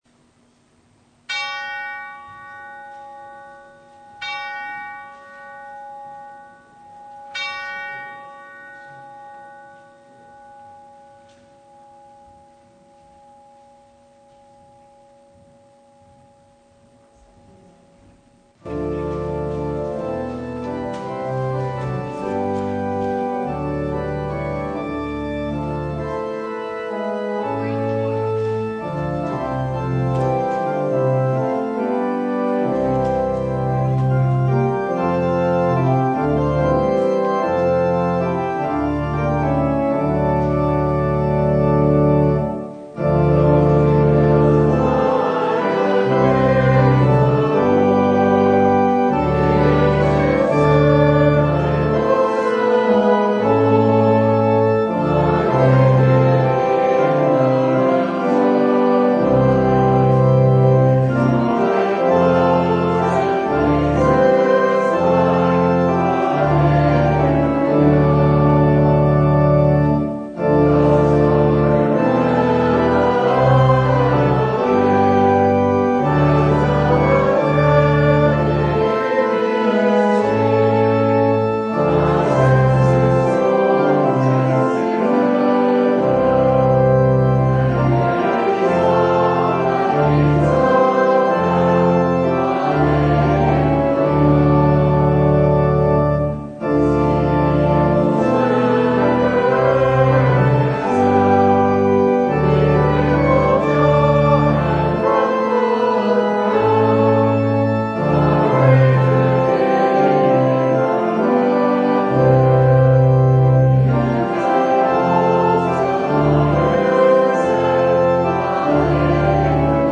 John 20:19-31 Service Type: Sunday Seeing is believing